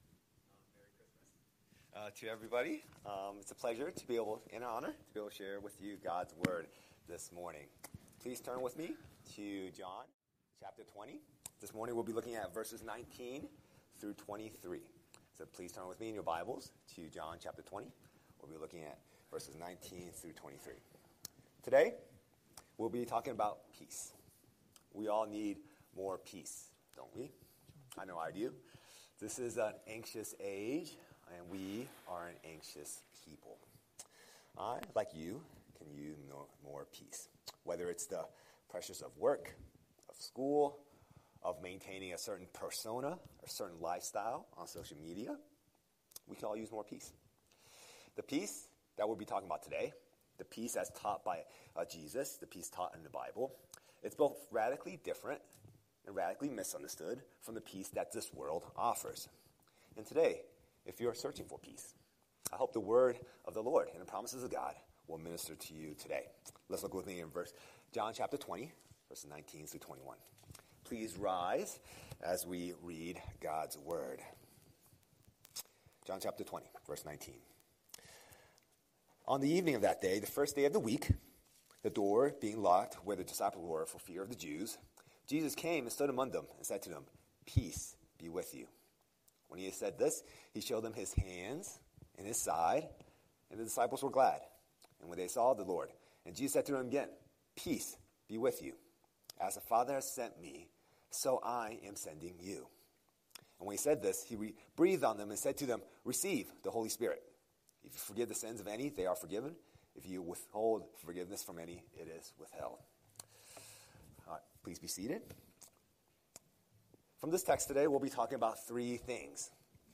Scripture: John 20:19–23 Series: Sunday Sermon